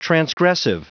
Prononciation du mot transgressive en anglais (fichier audio)